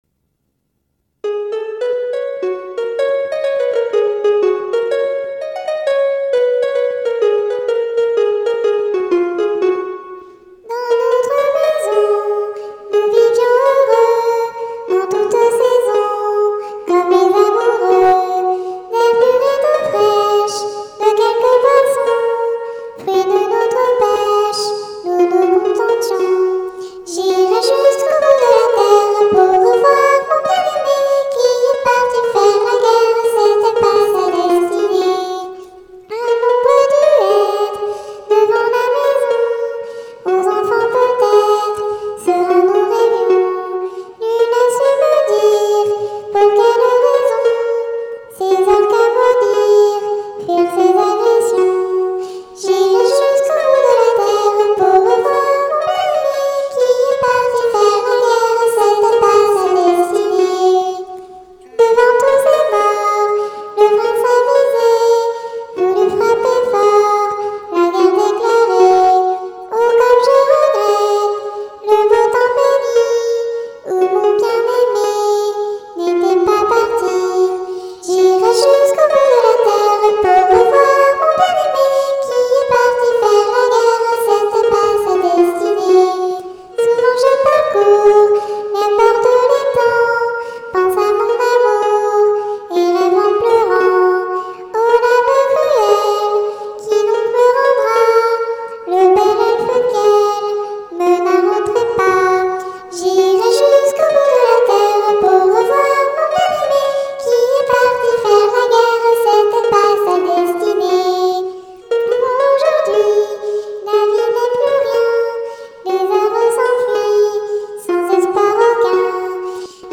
chant d'elfine.